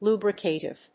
音節lu・bri・ca・tive 発音記号・読み方
/lúːbrəkèɪṭɪv(米国英語)/